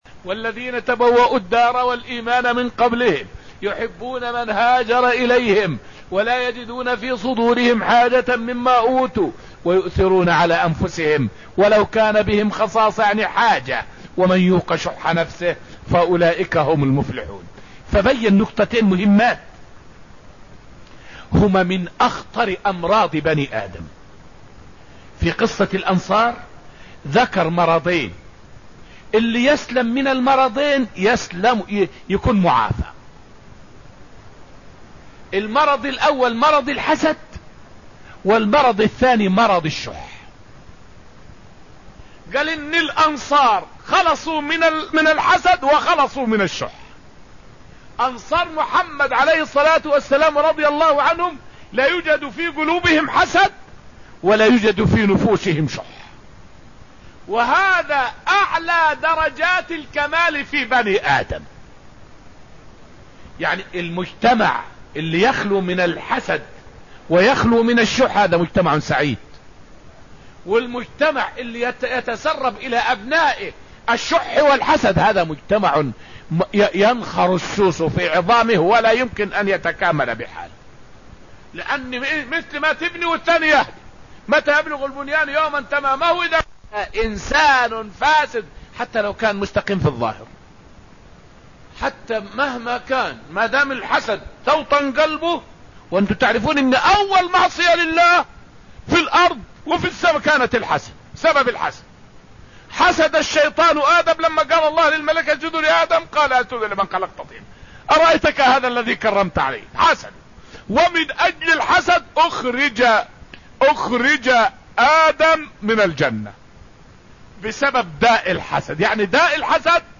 فائدة من الدرس السابع من دروس تفسير سورة الحشر والتي ألقيت في المسجد النبوي الشريف حول خطورة الحسد والشح على الأمم والأفراد.